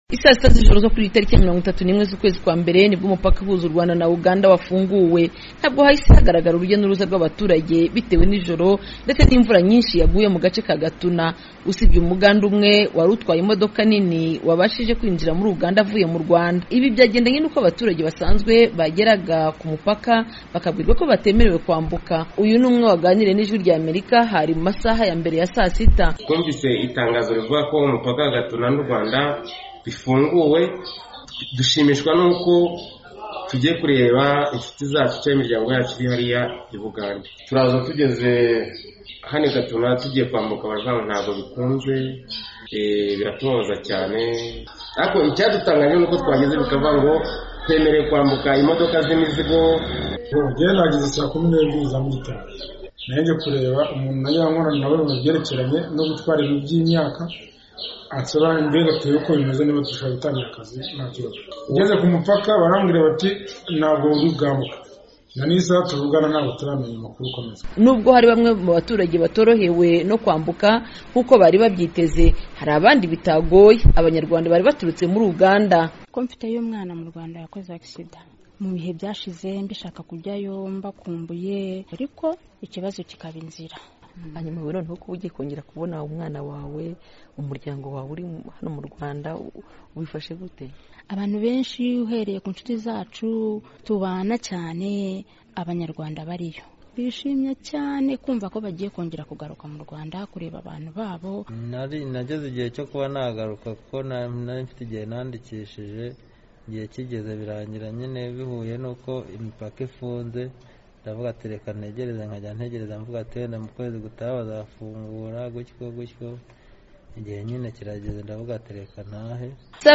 Ijwi ry'Amerika ryari ryarungitse ku mupaka wa Gatuna